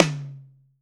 TOM 1H    -L.wav